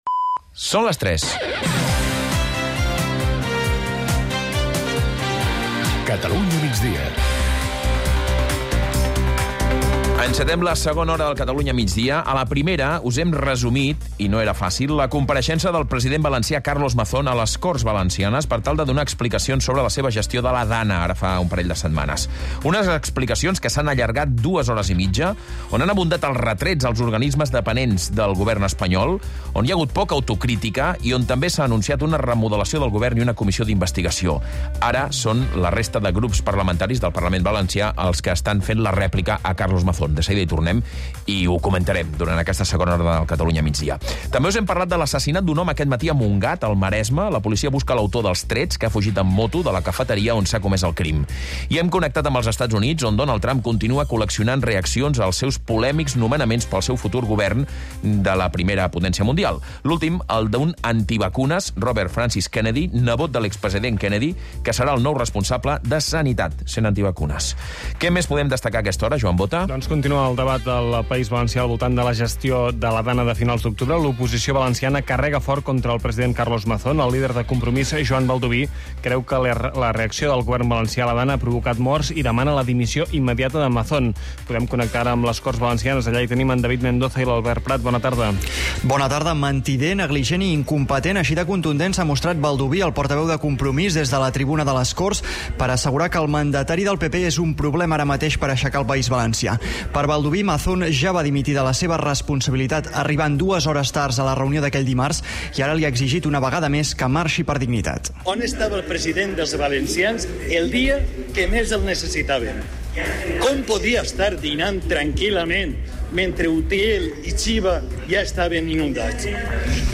… continue reading 503 ตอน # Society # Corporaci Catalana de Mitjans Audiovisuals, SA # Catalunya Rdio # News Talk # News